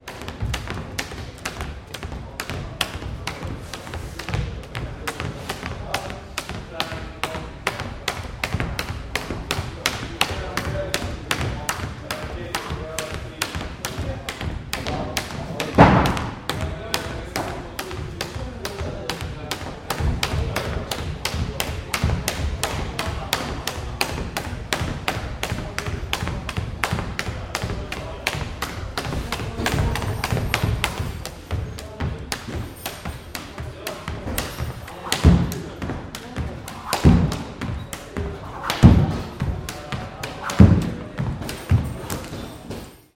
Звуки скакалки
Атмосферный звук из боксерского зала прыжки на скакалке